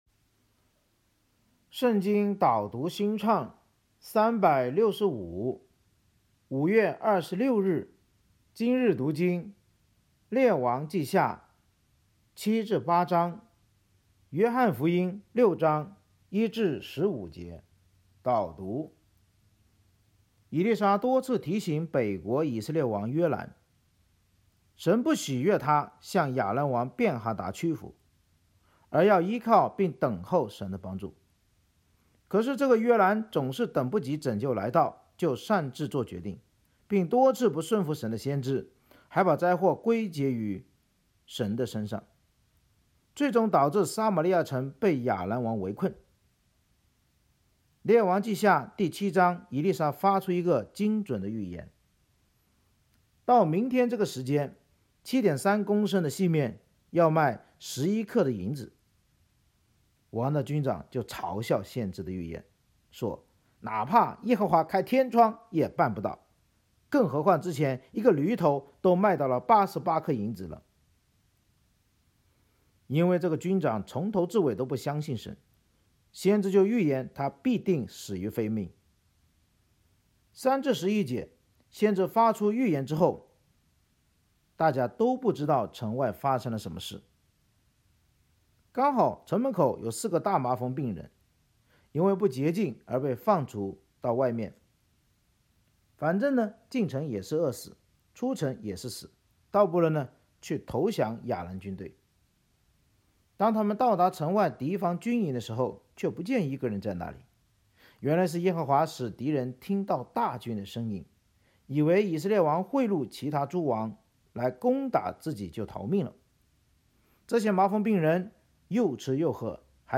圣经导读&经文朗读 – 05月26日（音频+文字+新歌）